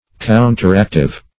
Counteractive \Coun`ter*act"ive\ (-?kt"?v), a.